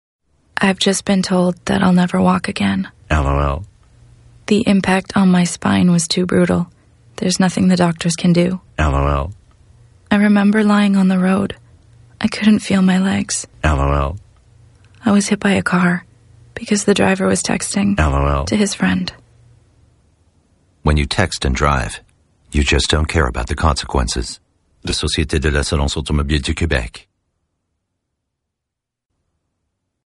SilverPublic Service - Radio Single